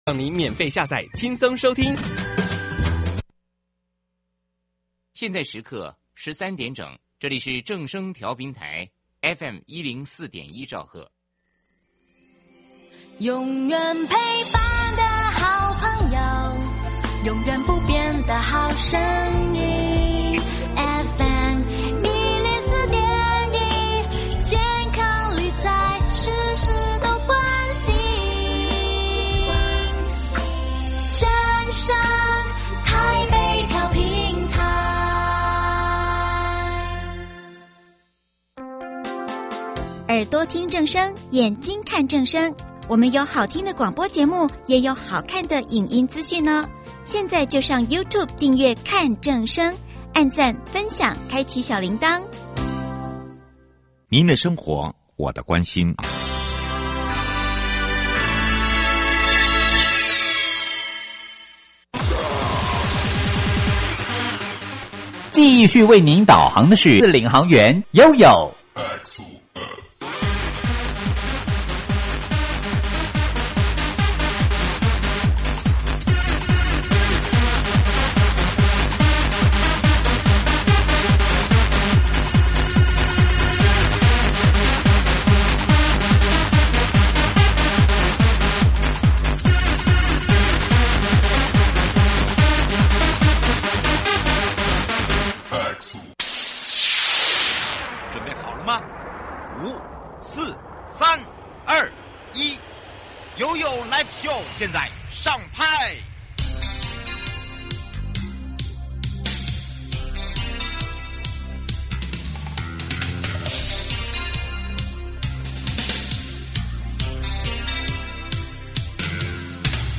節目內容： 嘉義縣政府建設處郭良江處長 (一)